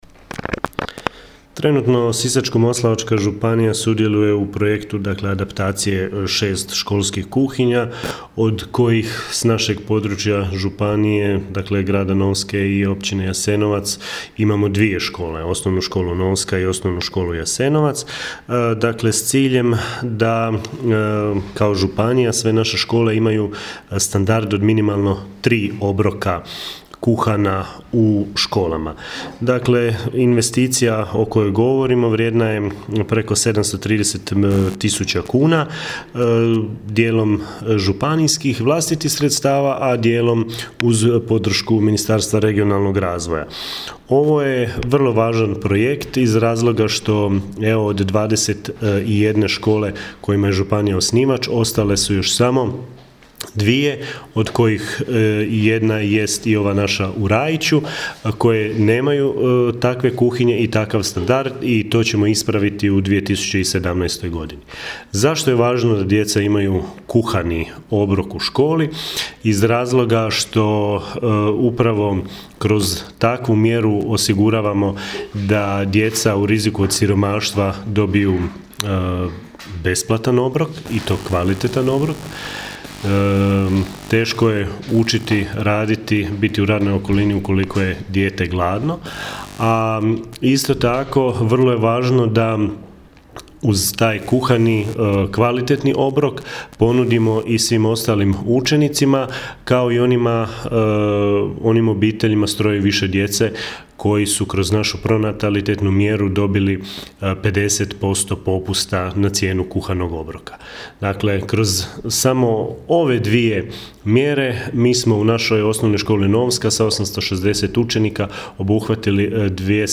- zamjenika župana